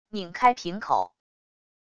拧开瓶口wav音频